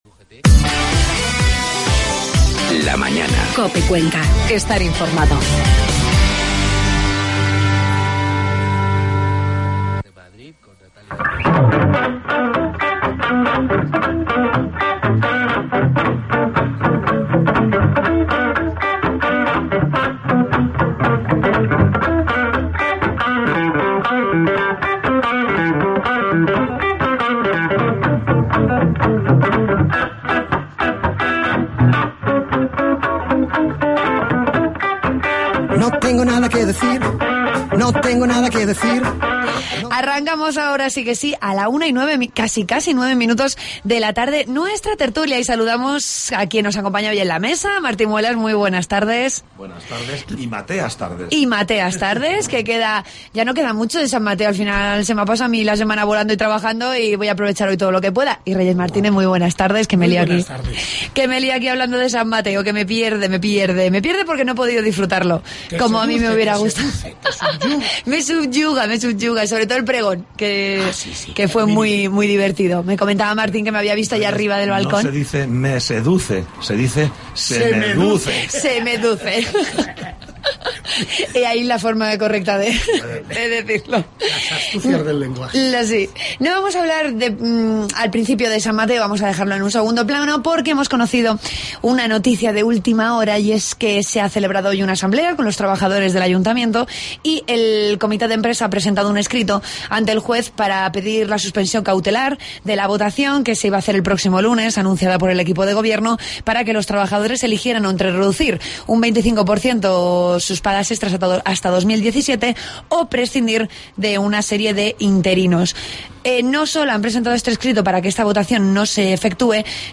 AUDIO: Ya puedes escuchar la tertulia de los viernes de COPE Cuenca